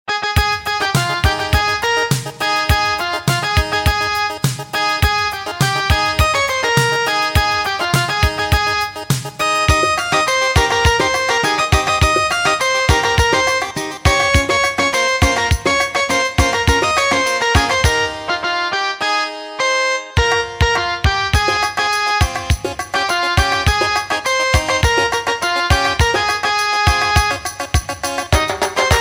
Bollywood